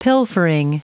Prononciation du mot pilfering en anglais (fichier audio)
Prononciation du mot : pilfering